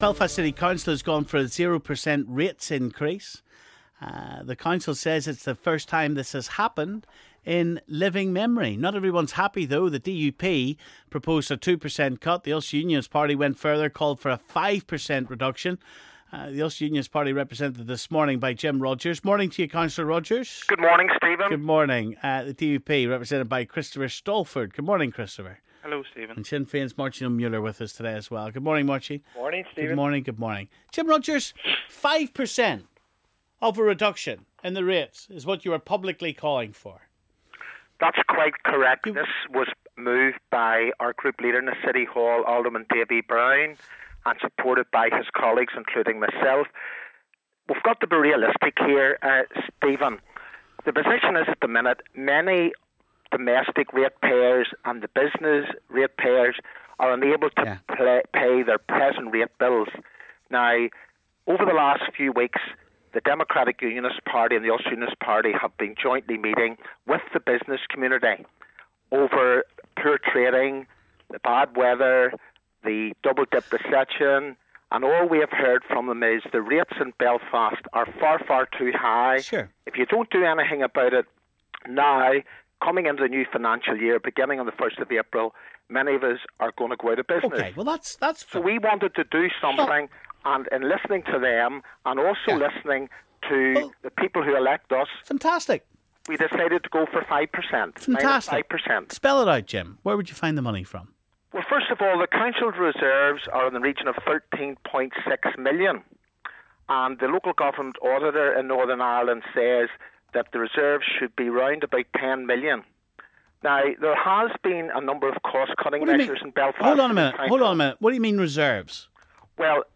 The DUP proposed a 2% cut, while the Ulster Unionist Party went further and called for a 5% reduction. We hear from UUP councillor Jim Rodgers, Sinn Fein's Mairtin O'Mulleoir and the DUP's Christopher Stalford.